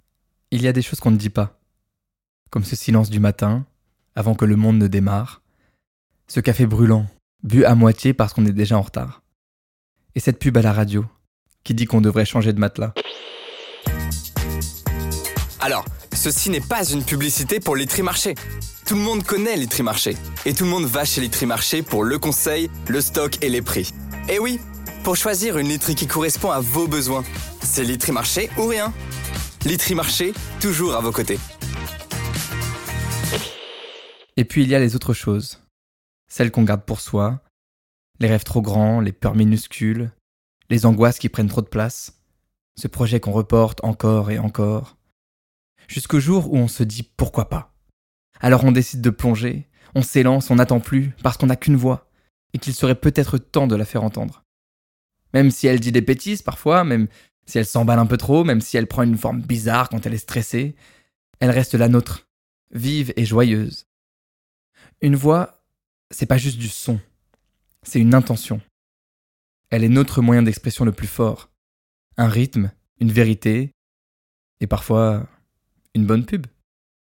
Bande démo vocale